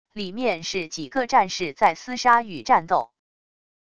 里面是几个战士在厮杀与战斗wav音频